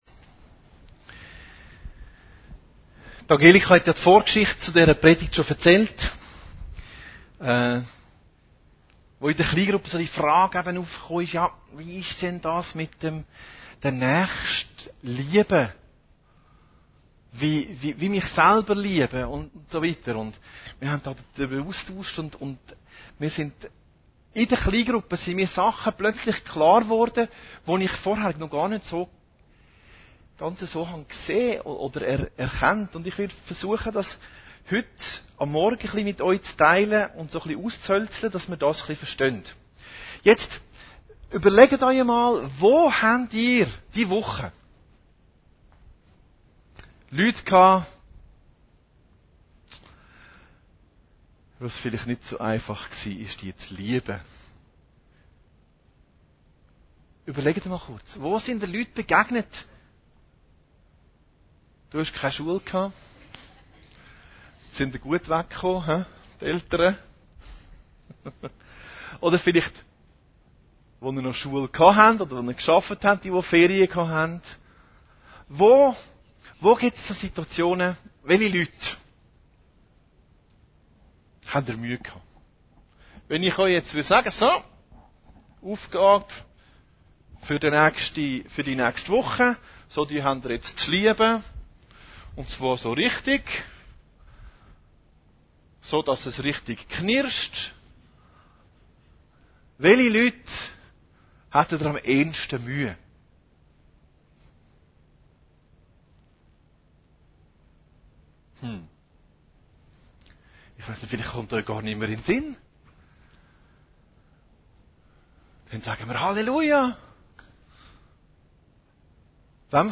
Predigten Heilsarmee Aargau Süd – Nächstenliebe